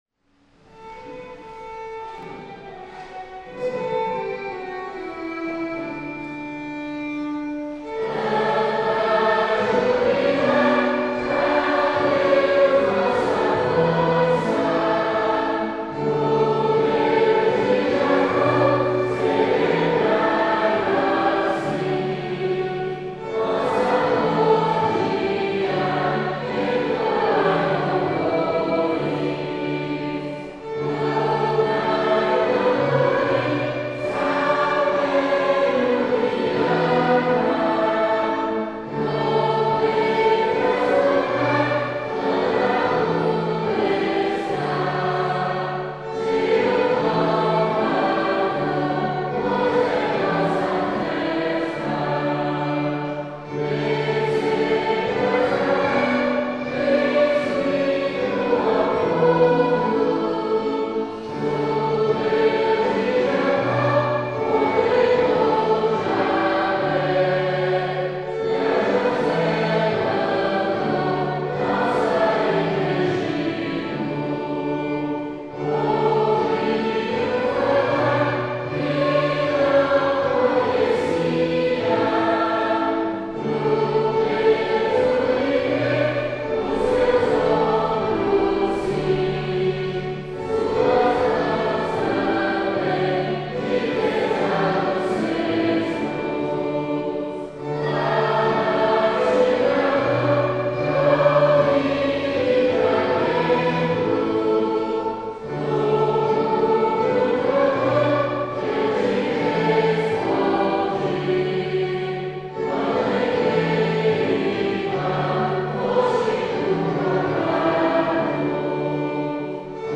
Modo: jônio
Harmonização: Claude Goudimel, 1564
salmo_81A_cantado.mp3